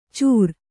♪ cūr